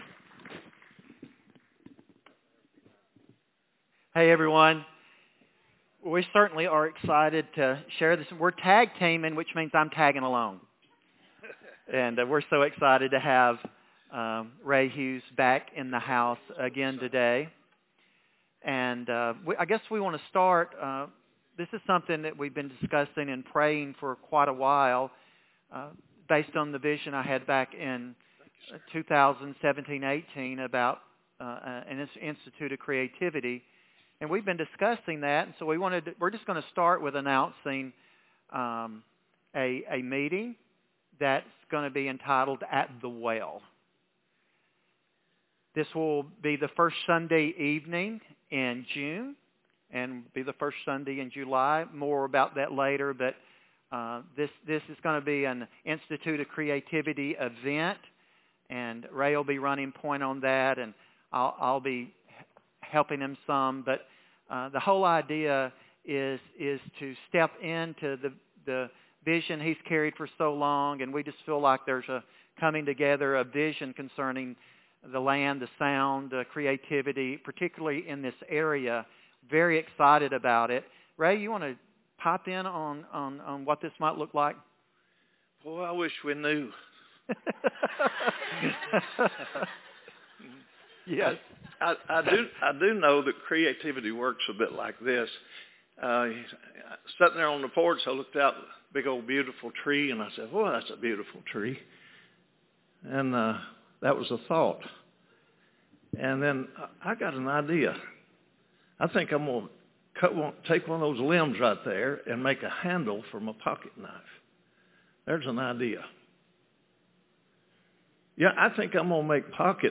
Guest Speaker
Current Sermon